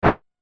/ F｜システム電子音 / F-02 ｜システム2 さらにシンプルな電子音 カーソル移動などに
システム電子音(シンプル-短) 100 選択 007 スイング
バン